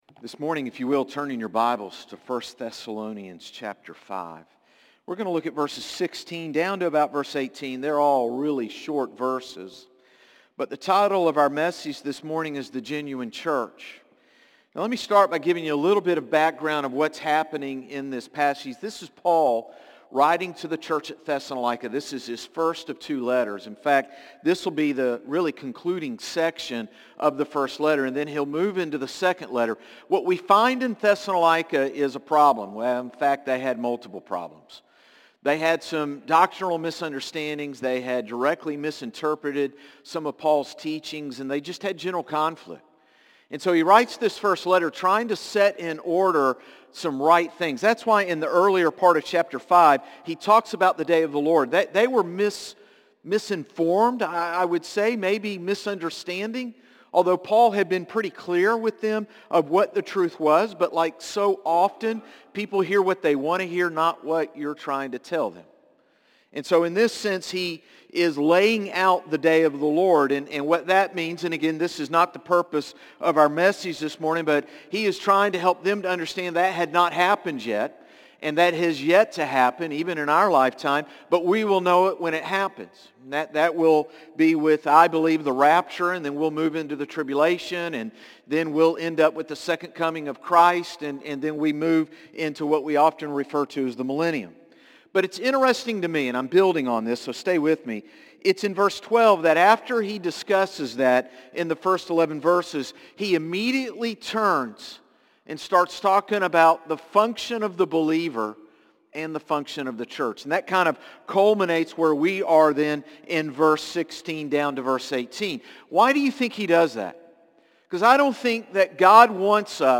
Sermons - Concord Baptist Church